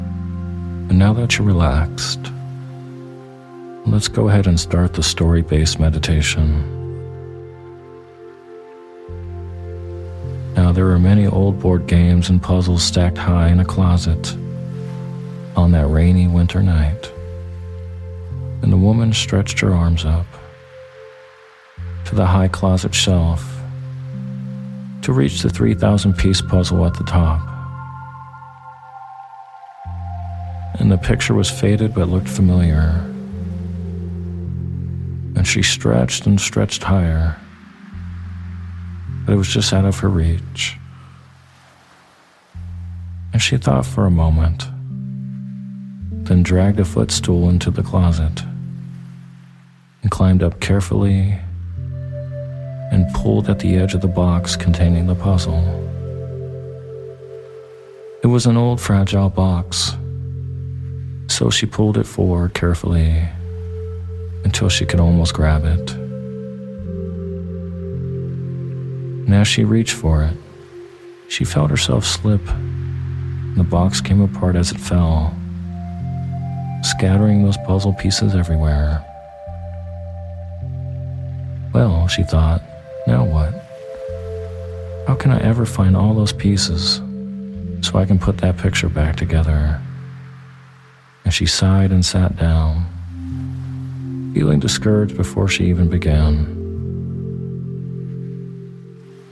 Story Based Meditation "The Puzzle"